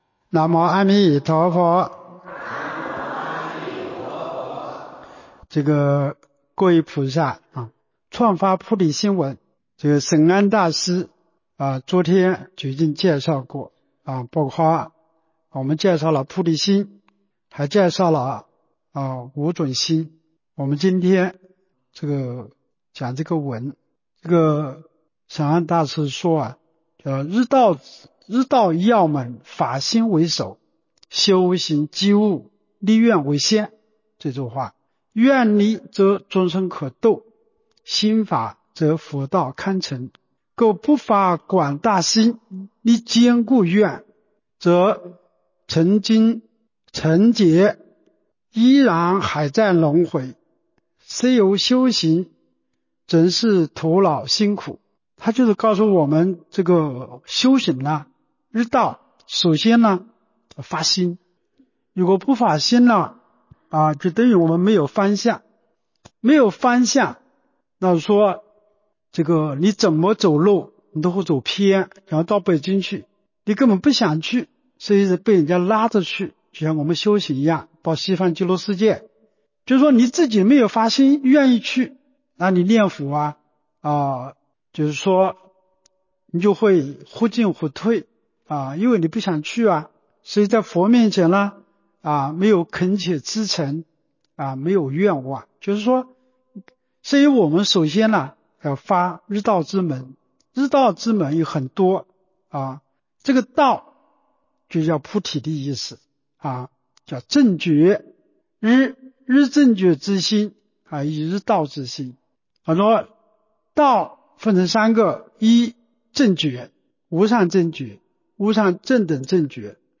彭泽集福寺佛七开示